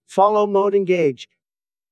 follow-mode-engaged.wav